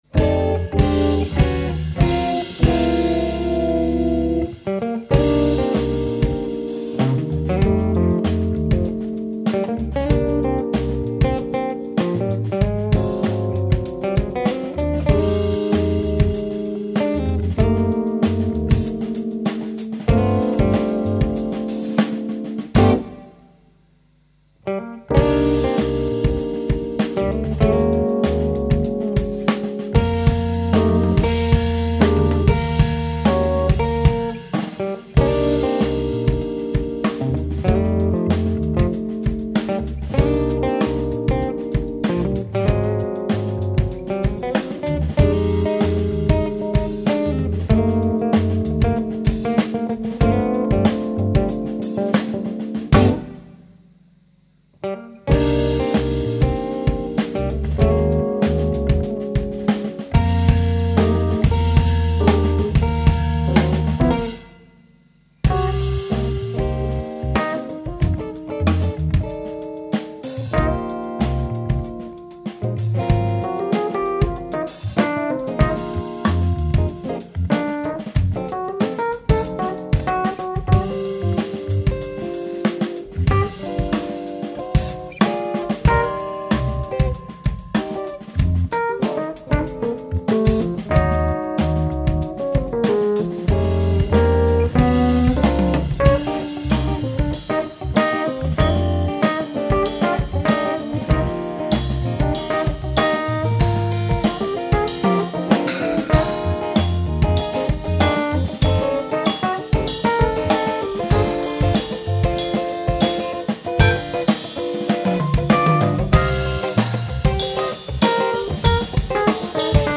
Le projet avec le trio jazz funk avance, on a enregistré une maquette le WE dernier.
Prise de son très clean et rythmiquement ça tourne pile poil.
ça groove